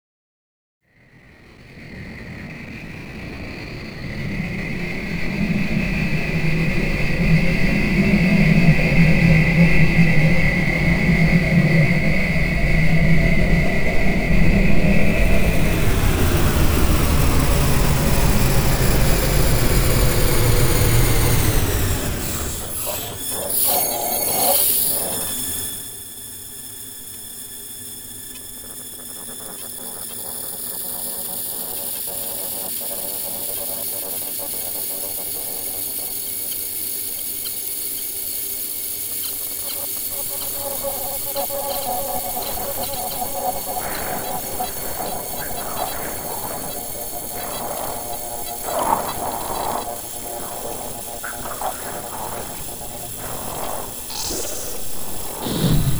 5 Studies of Artificial Environments Sound Installation The sound segments contained in this project are studies of urban sound environments. Sounds are captured using a pair of binaural field microphones which preserve the delicate cues our ears use to perceive the various sounds in a given soundscape. The recordings are then brought into the digital domain for editing and mixing. Many of these recordings highlight those aspects of sound often viewed as undesirable, including noise from wind and air traffic, electrical interference, spontaneous recording artifacts, and sudden acoustical interruptions. Some segments contain immediately recognizable sounds, while others have been intentionally manipulated beyond the point of recognition. Sounds for this project were collected from many locations around the world, including Taipei, New Orleans, Detroit, Toledo, and the Chicago area.